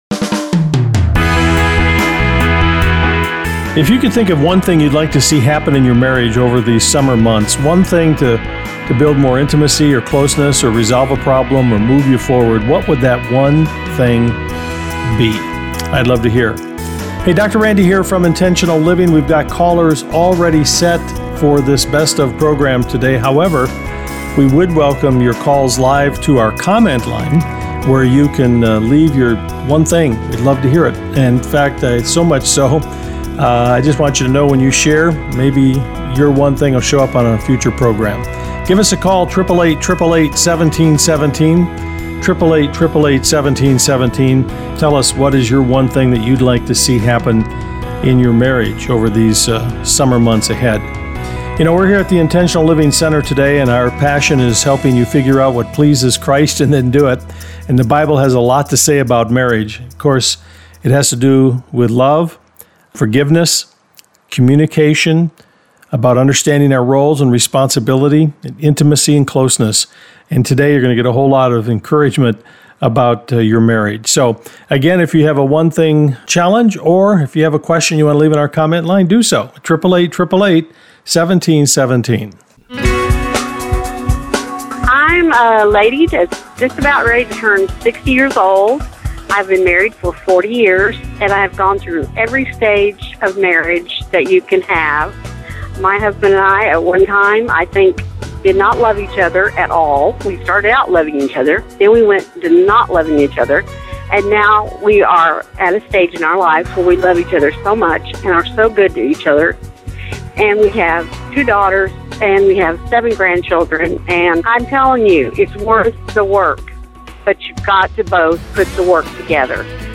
Open Phones: Marriage – Intentional Living